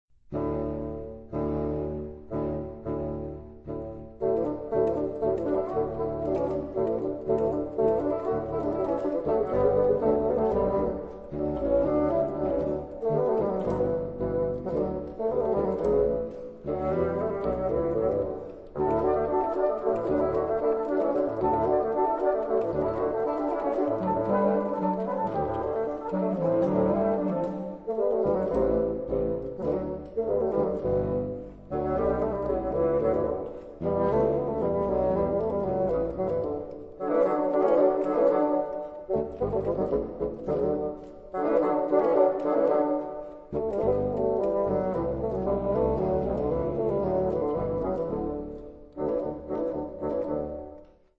Cette danse de forme ABA emploie des formules mélodiques simples, une rythmique carrée et une harmonie rudimentaire teintée parfois de modalité.
L'antécédent est placé sur double-pédale de tonique et de dominante formant bourdon
Le conséquents dans une stricte écriture à 3 voix, présente une ligne mélodique à l'ornementation clavecinistitique.
B- Cette partie centrale moins développée que A propose un thème en périodes de trois mesures accompagné par des quintes consécutives.
A- La reprise est écourtée.